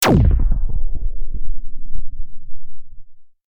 Paradise/sound/weapons/emitter2.ogg
Added new weapon sounds: blaster, laser, pulse, wave, emitter, and one for the marauder canon.